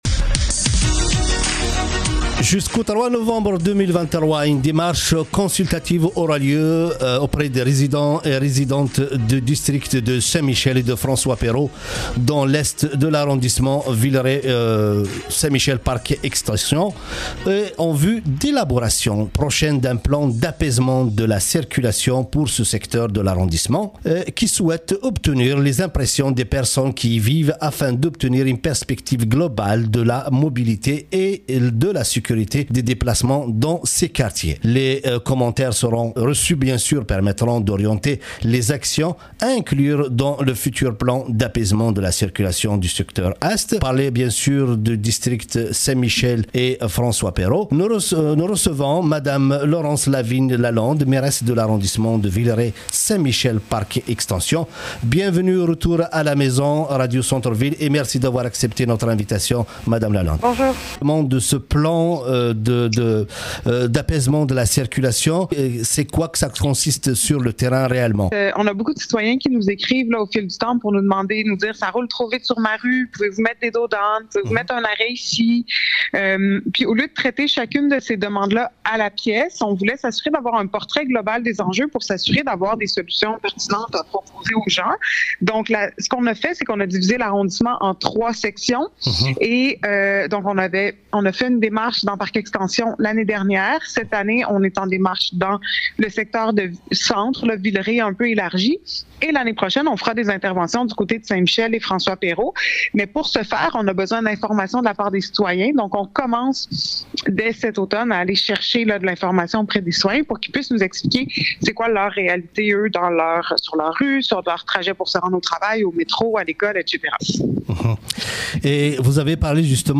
Entrevue-mairesse-VPSM-.mp3